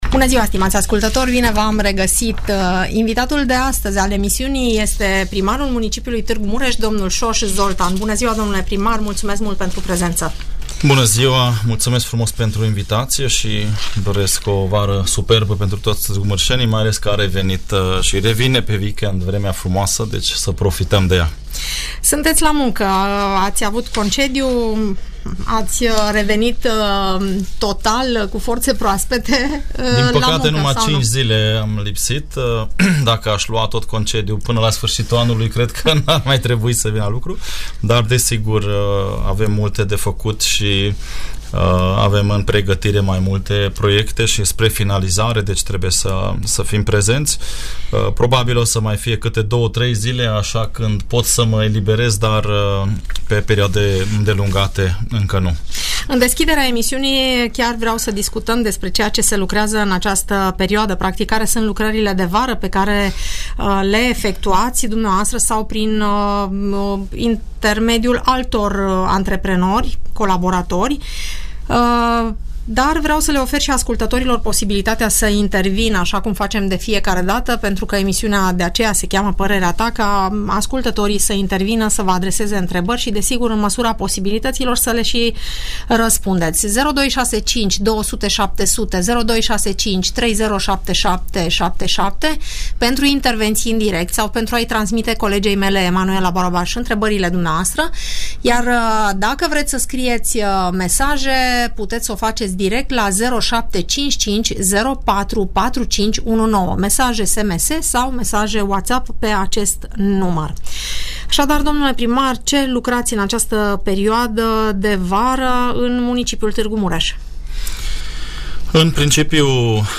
Salubrizarea și deratizarea, lipsa drumurilor ocolitoare sau gropile din asfalt sunt câteva dintre problemele pe care târgumureșenii i le-au semnalat primarului Soós Zoltán, invitat în direct la Radio Tg. Mureș.